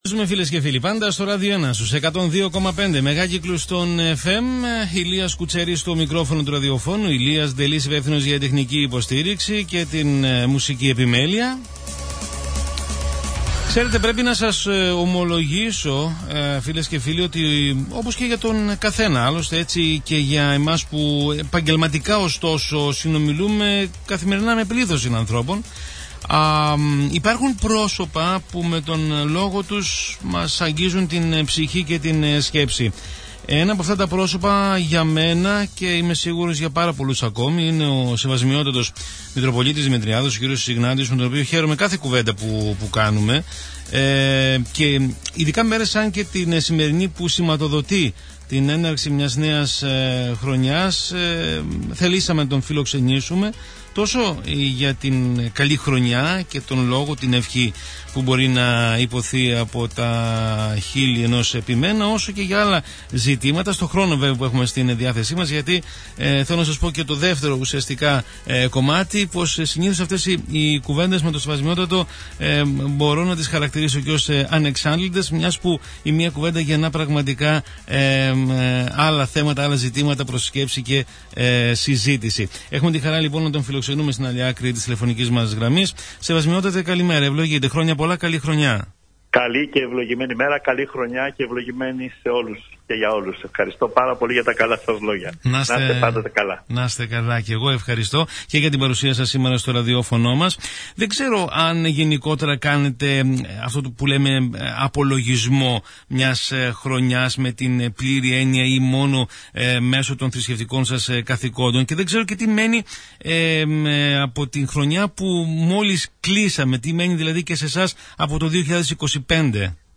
Ο απολογισμός της χρονιάς από τον Μητροπολίτη Δημητριάδος στο Ράδιο ΕΝΑ(Ηχητικό)